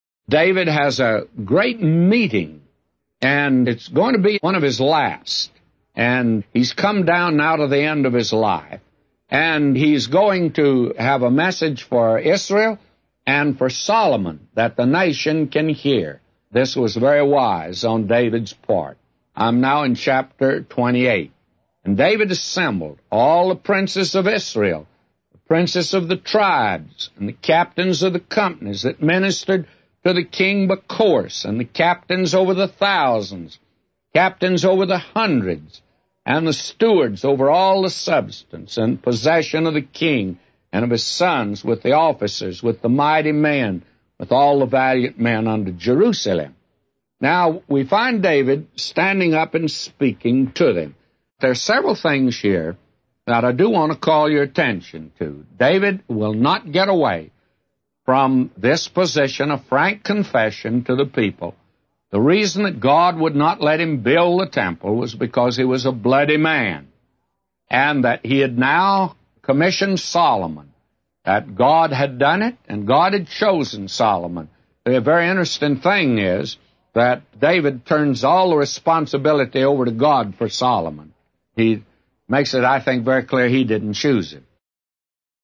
A Commentary By J Vernon MCgee For 1 Chronicles 28:1-999